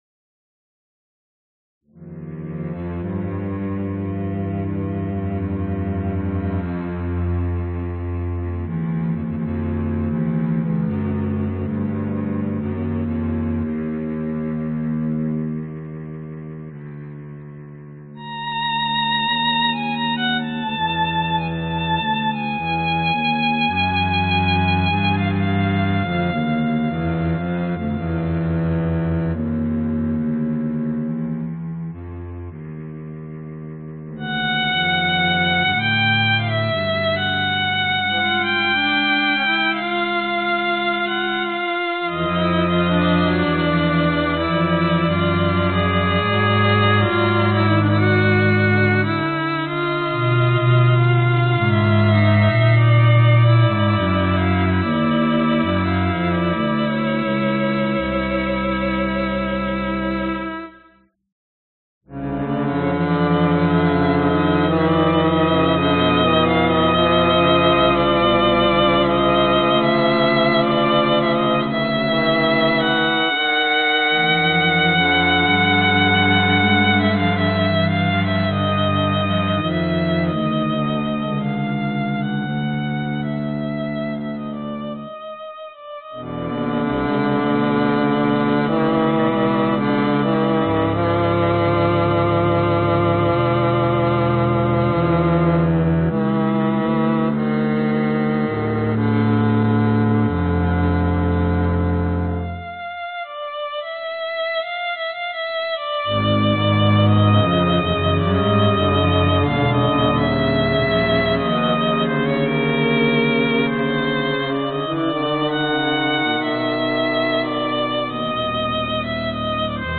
描述：用Akai EWI USB（电子管乐器）演奏。
Tag: 弦乐 管弦乐